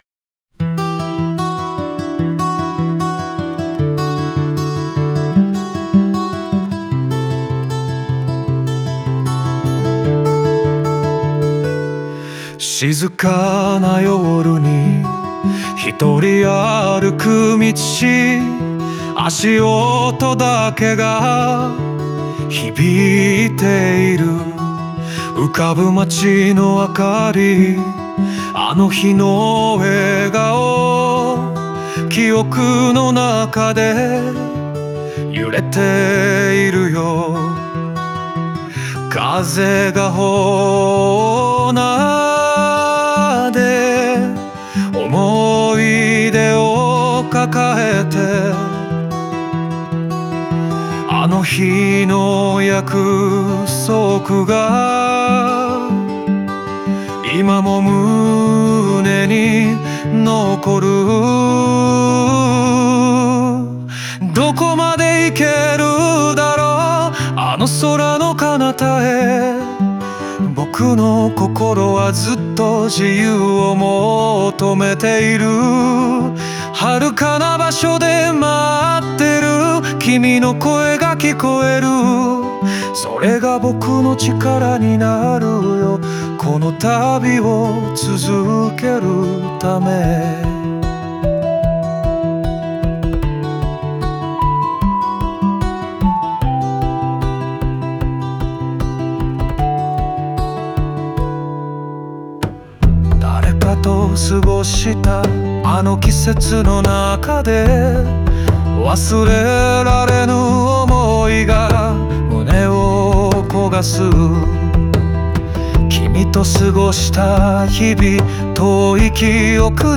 間奏では、リズムが軽やかになり、旅の先にある希望を感じさせます。